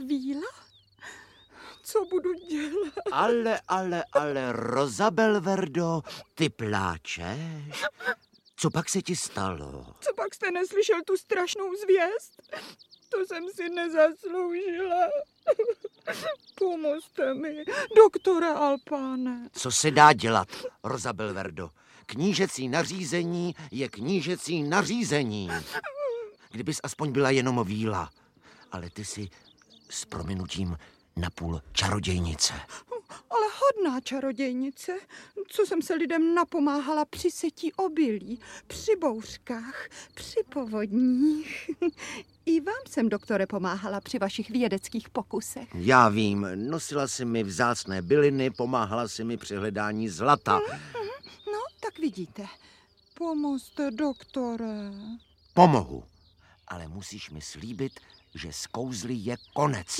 Audiobook
Read: Libuše Švormová